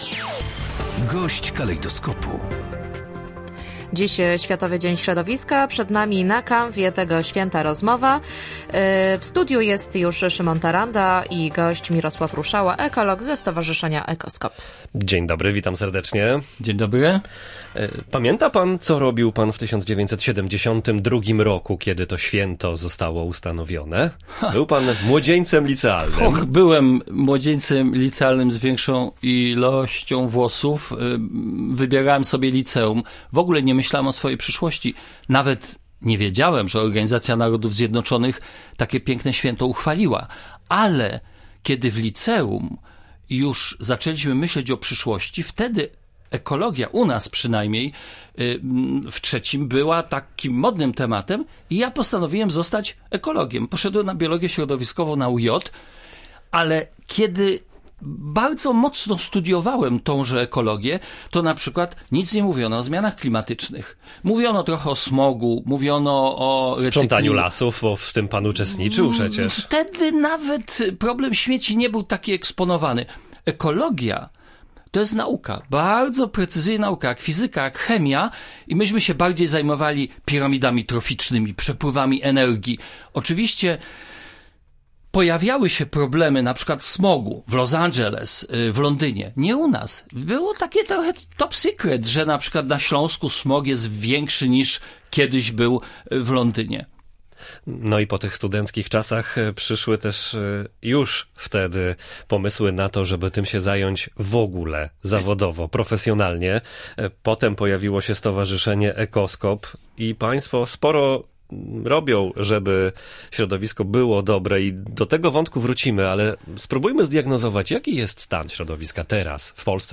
GOŚĆ DNIA: Zmiany klimatu, takie jak powodzie będą się nasilać